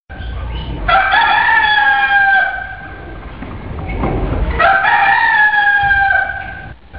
Traditional Rooster Tattoo Sound - Botón de Efecto Sonoro